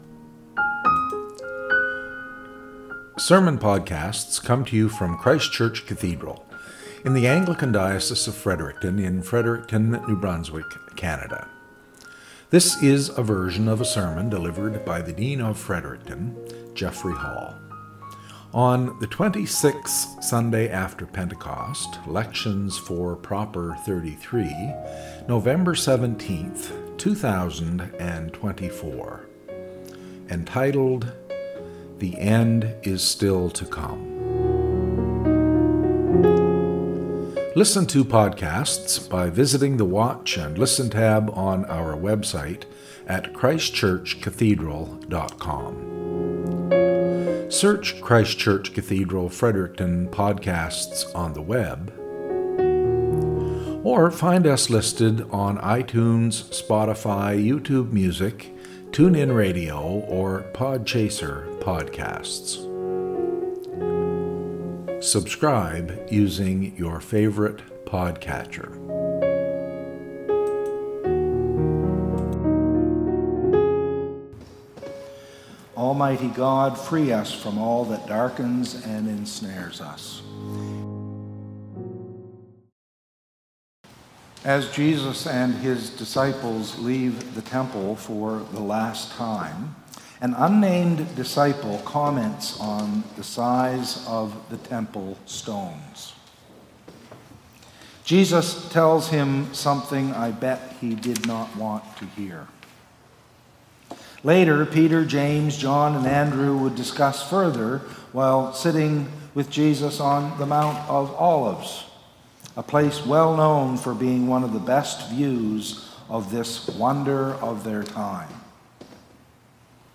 Cathedral Podcast - SERMON -